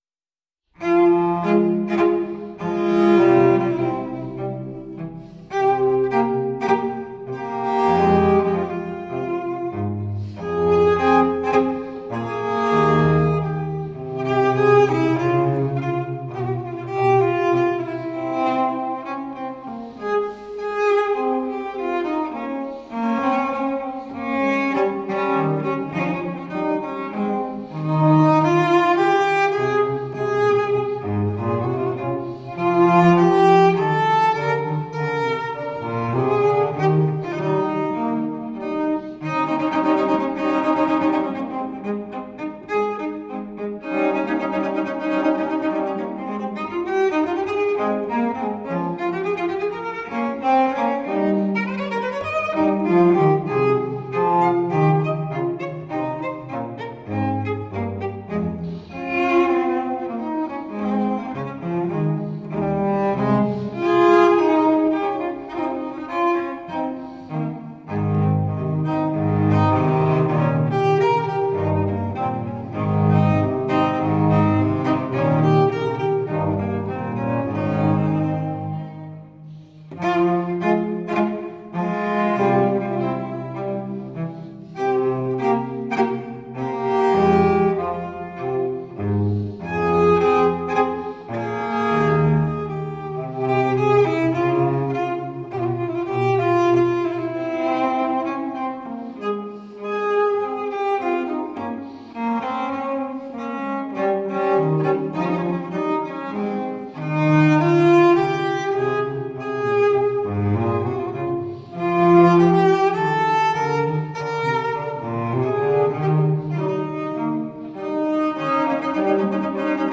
Classical, Classical Period, Instrumental Classical, Cello